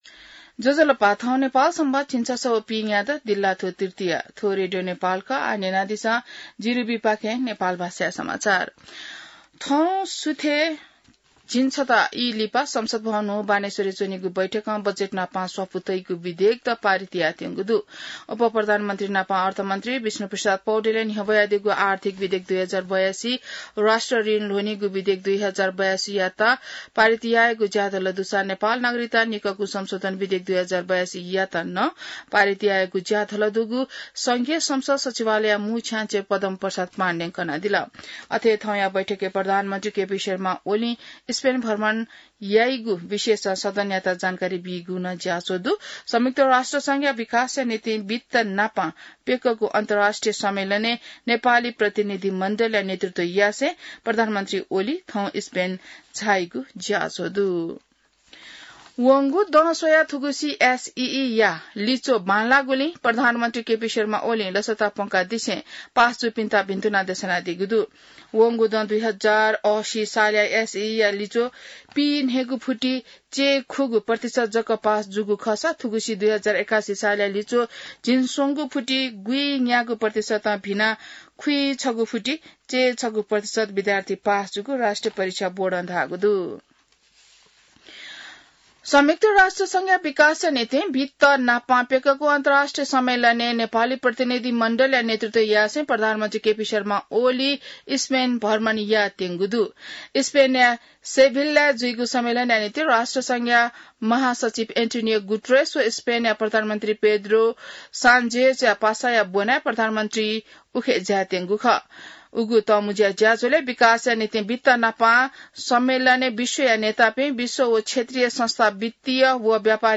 An online outlet of Nepal's national radio broadcaster
नेपाल भाषामा समाचार : १४ असार , २०८२